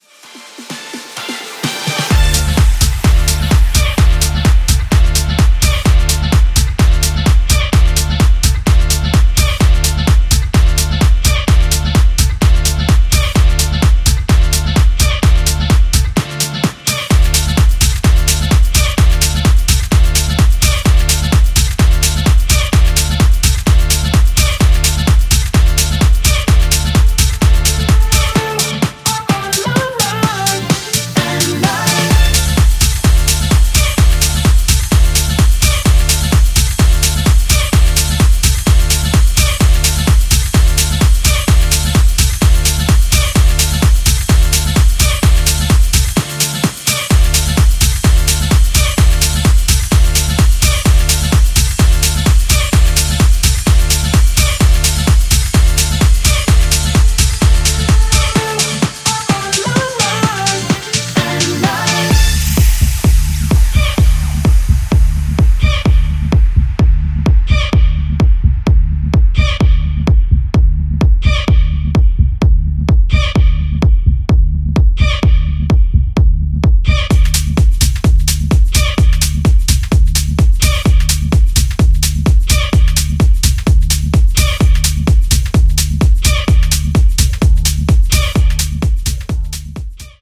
VIP EXTENDED EDIT
ジャンル(スタイル) DEEP HOUSE / DISCO HOUSE